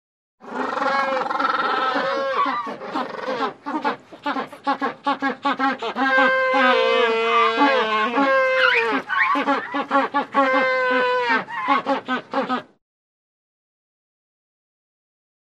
animal
Magellanic Penguins Small Group Braying 1